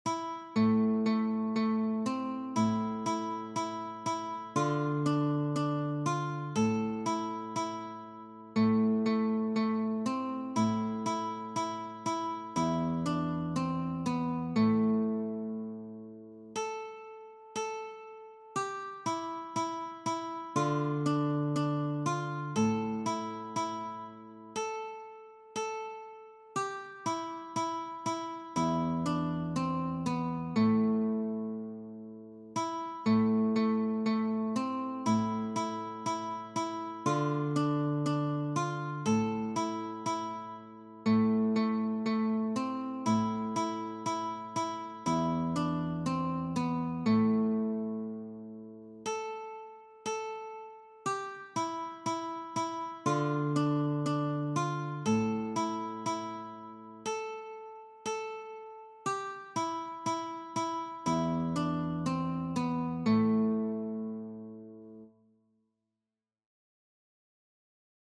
Sololiteratur
Gitarre (1)